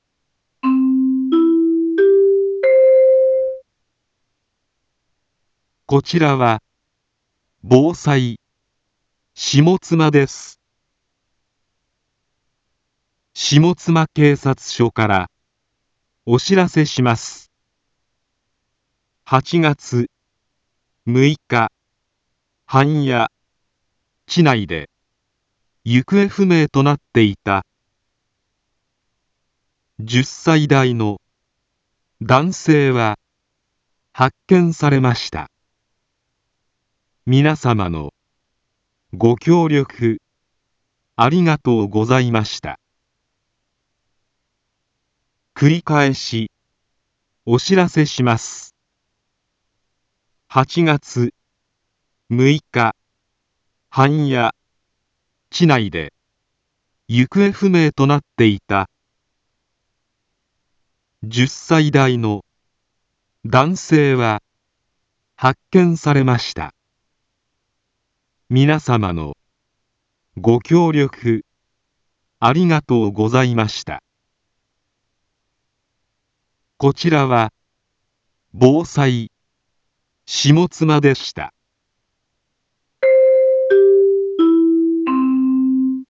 一般放送情報
Back Home 一般放送情報 音声放送 再生 一般放送情報 登録日時：2025-08-08 17:53:46 タイトル：行方不明者発見について インフォメーション：こちらは、防災、下妻です。